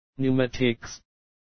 Numatics读音
Numatics英文读“纽曼蒂克”，可点击以下Numatics的在线英文读音：
NUMATICS英语发音